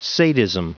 Prononciation du mot sadism en anglais (fichier audio)
Prononciation du mot : sadism